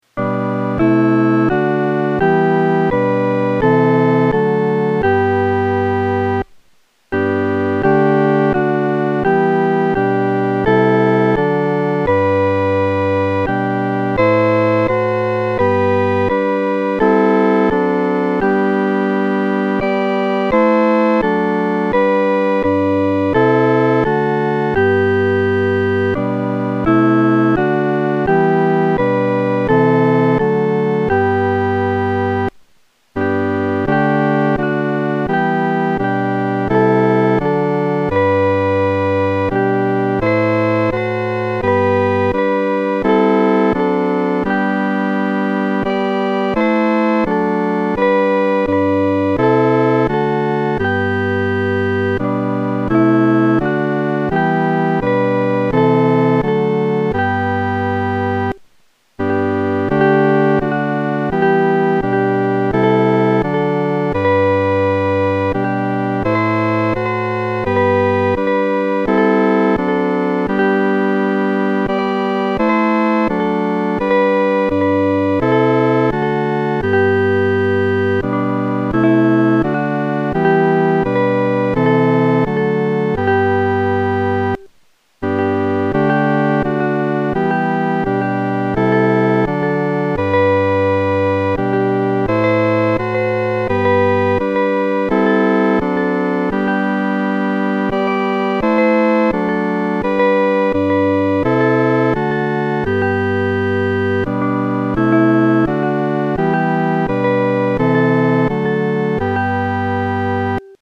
伴奏
四声
这首诗歌充满着虔敬和恳切，我们在弹唱时的速度不宜太快。